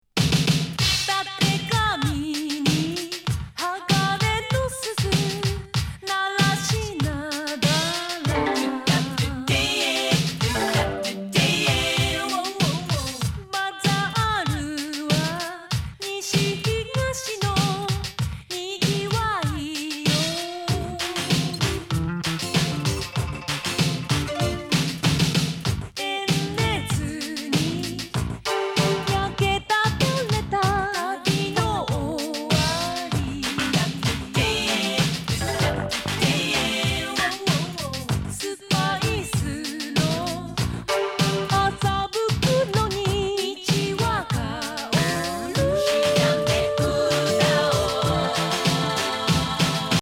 ＆ボーカル入りVER◎です！